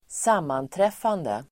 Uttal: [²s'am:antref:ande]